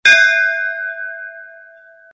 Home >> Hindu >> BellSound
dingg-regular.mp3